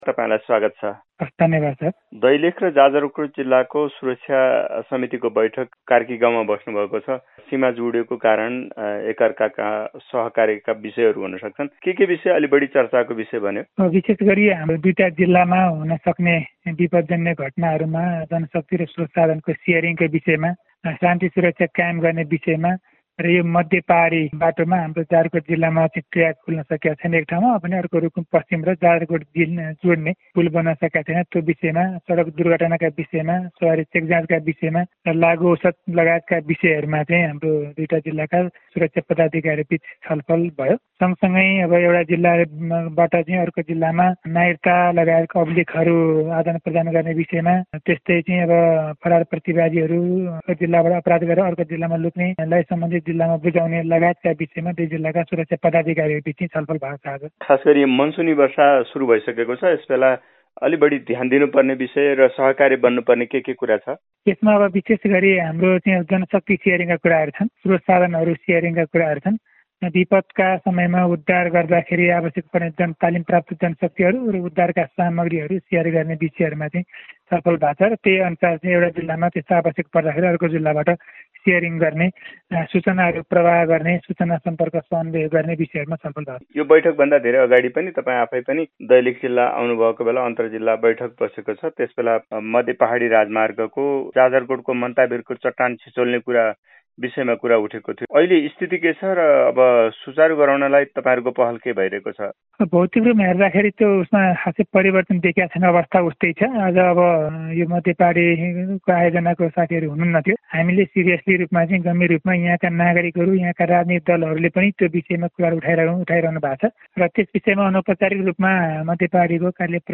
अन्तरजिल्ला सुरक्षा बैठक बारे जाजरकोटका प्रजिअ मेखबहादुर मग्रातीसंग गरिएकाे कुराकानी –
Jajarkot-CDO-Mekh-Bahadur-Magrati-1.mp3